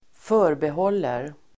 Uttal: [²f'ö:rbehål:er]